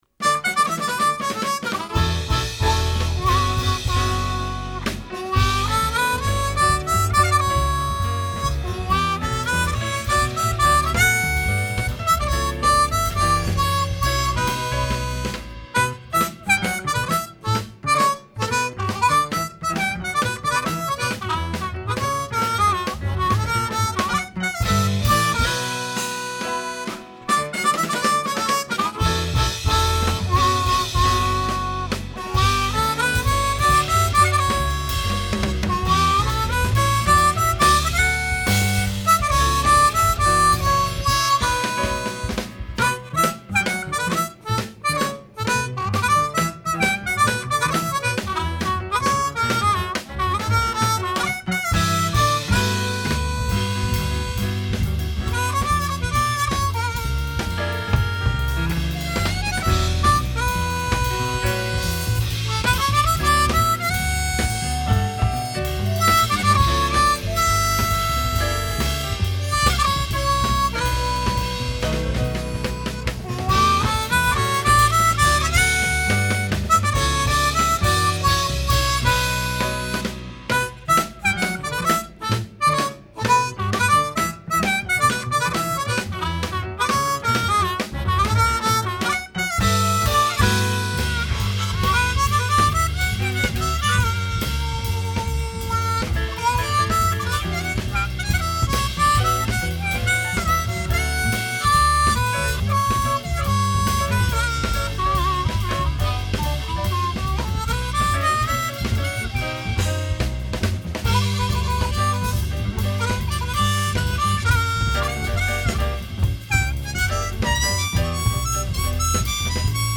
Standard Richter diatonic harmonicas.